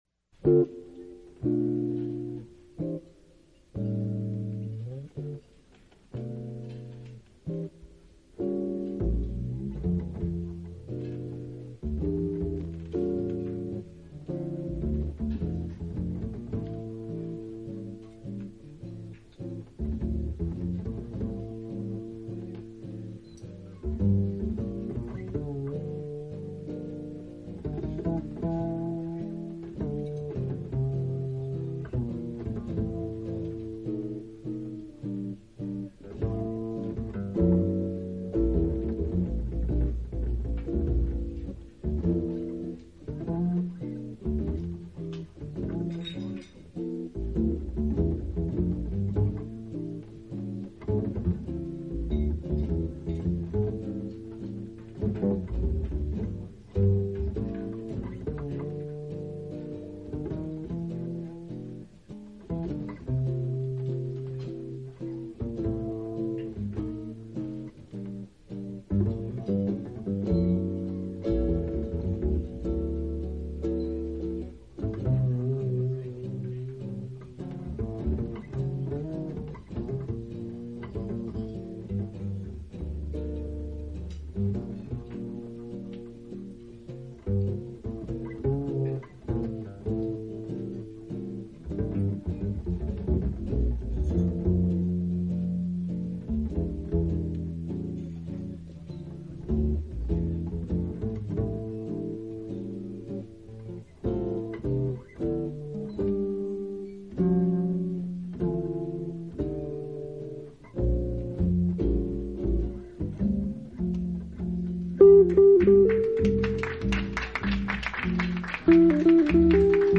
Format: Live
他的吉他感觉很和谐，不是那种很有爆发力的，但是非常值得 去细细品味。